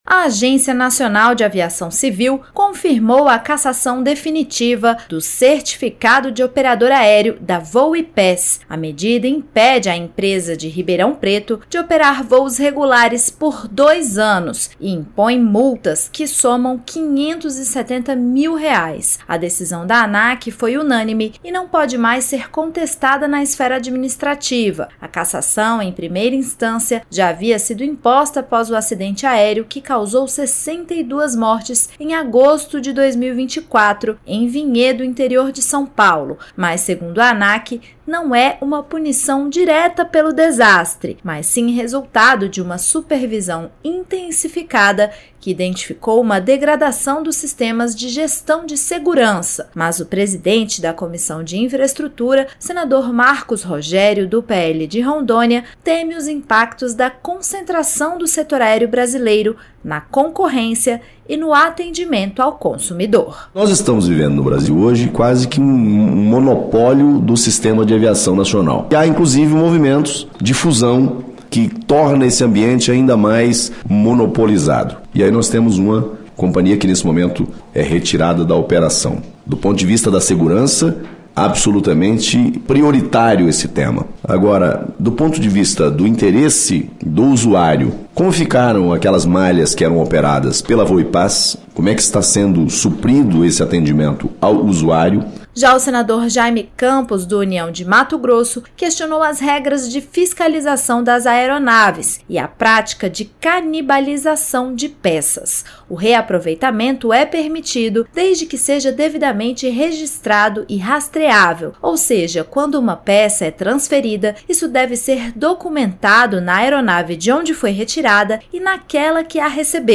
O presidente da Comissão de Infraestrutura, senador Marcos Rogério (PL-RO), alertou para o risco de monopólio no setor aéreo e prejuízos ao consumidor com diminuição das rotas. Já o senador Jayme Campos (União-MT) criticou a Anac por permitir a “canibalização” de peças, ou seja, o seu reuso por outras aeronaves.